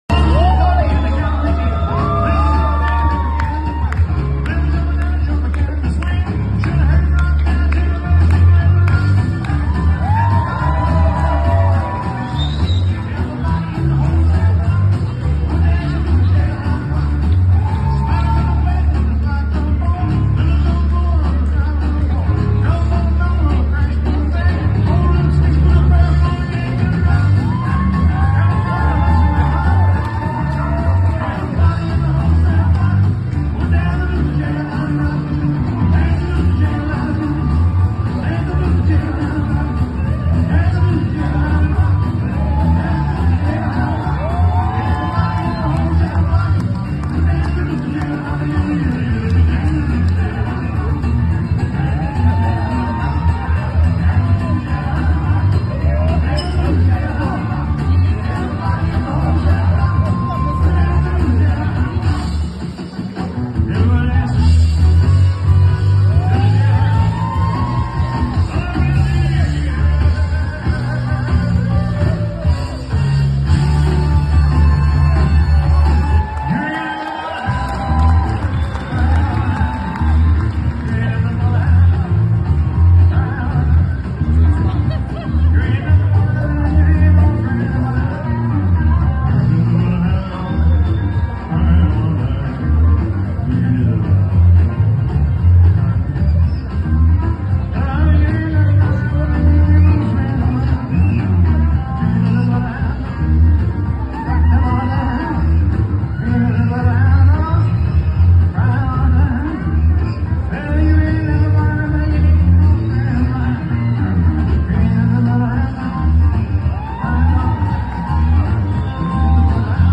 in der 1977er Version